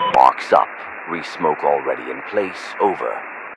Radio-jtacSmokeAlreadyOut2.ogg